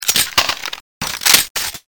Handcuff
Category: Sound FX   Right: Personal